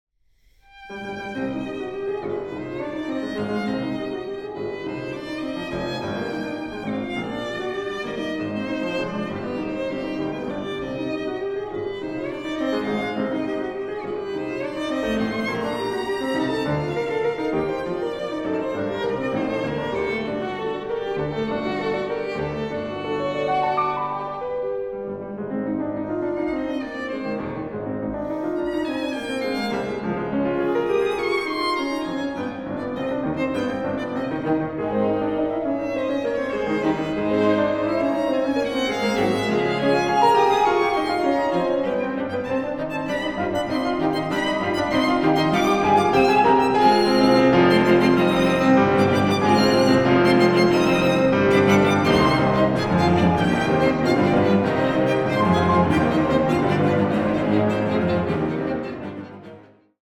Klavierquartett